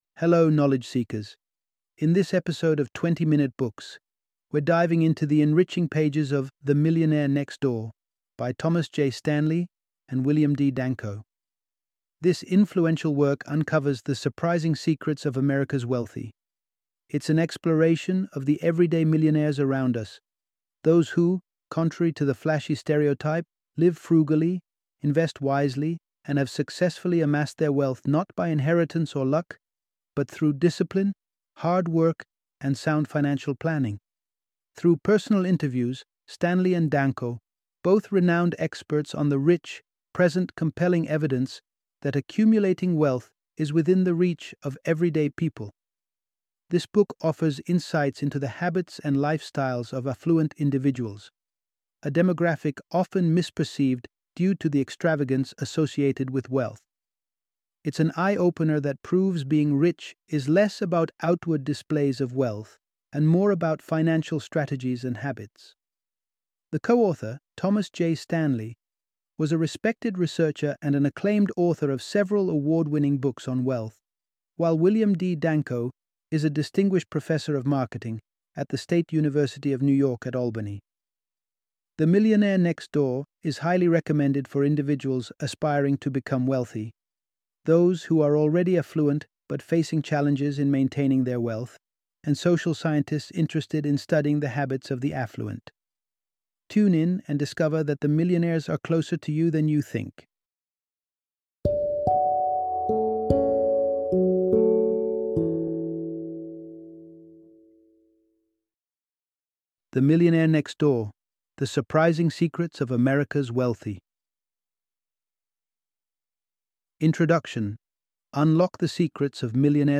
The Millionaire Next Door - Audiobook Summary